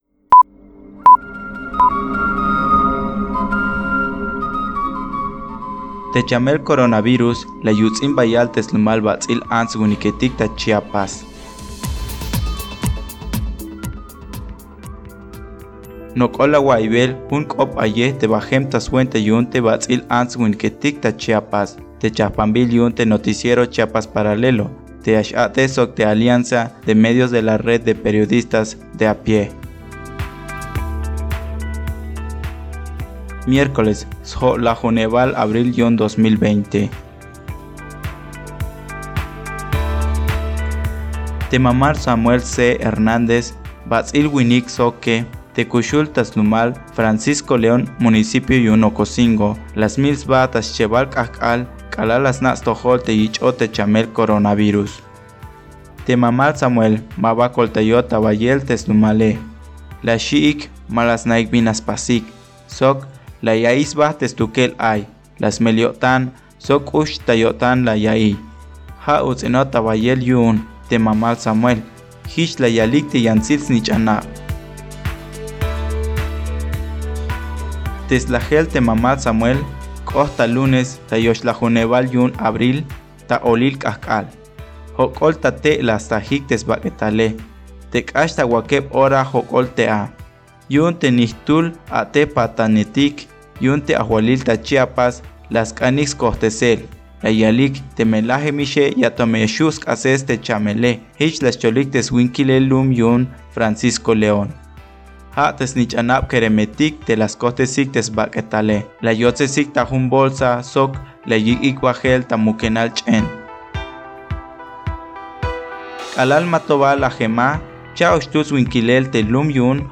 Locución (xcholel k’op ayej):